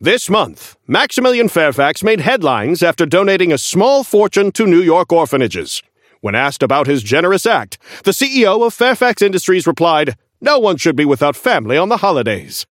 Newscaster_seasonal_headline_04.mp3